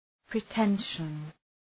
Προφορά
{prı’tenʃən}